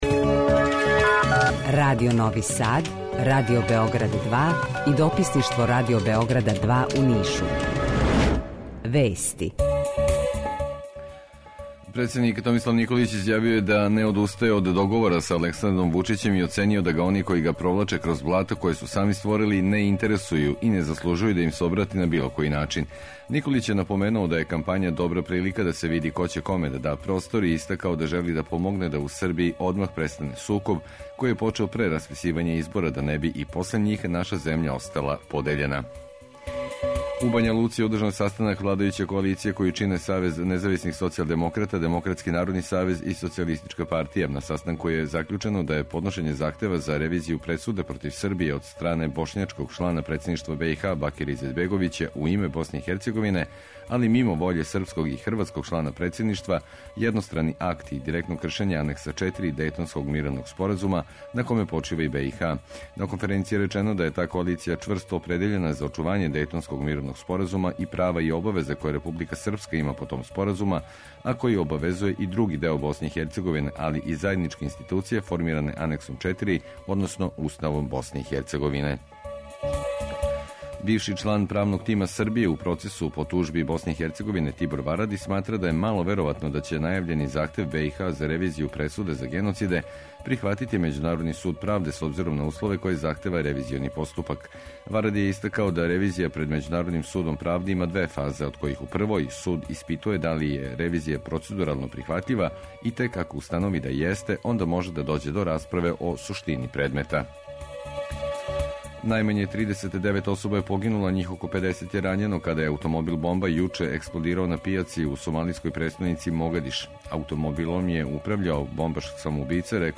Jутарњи програм заједнички реализују Радио Београд 2, Радио Нови Сад и дописништво Радио Београда из Ниша.
У два сата биће и добре музике, другачије у односу на остале радио-станице.